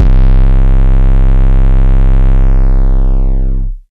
808 - Mafia.wav